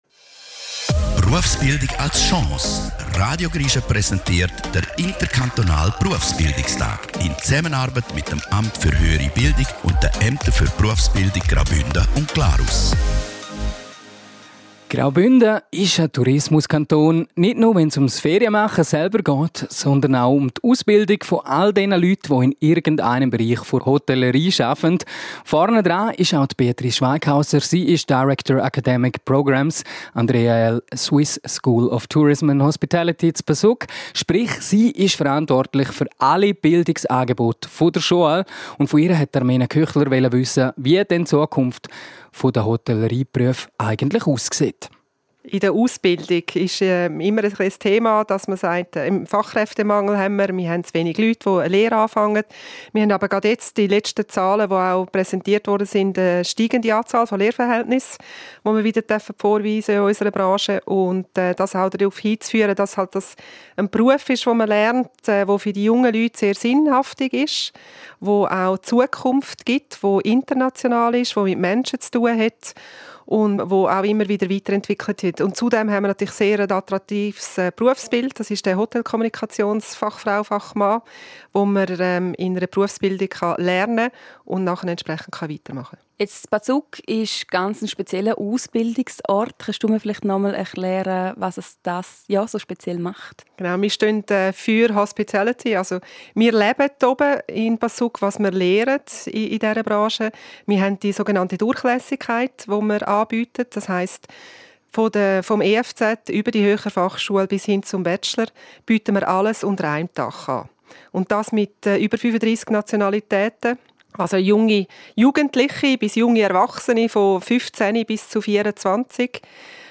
Zusammen mit Radio Grischa stehen folgende Themen im Fokus:
Mitschnitt Interk. Berufsbildung (Hotellerie).MP3